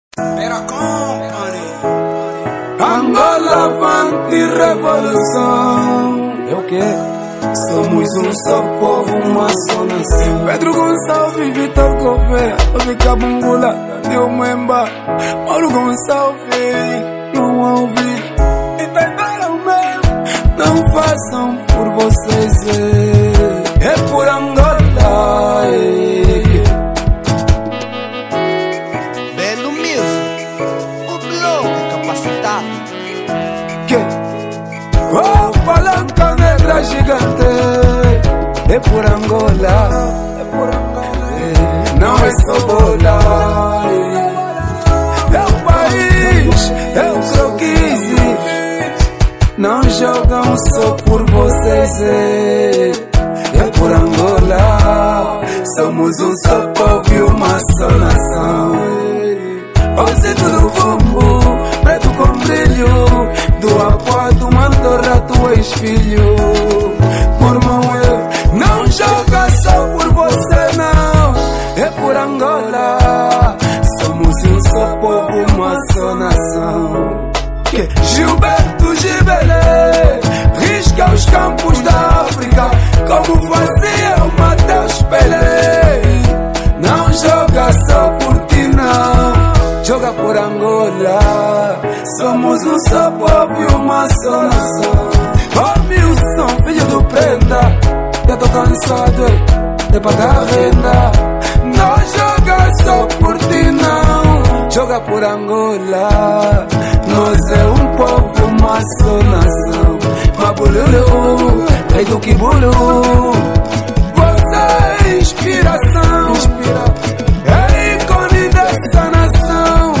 Género : Kuduro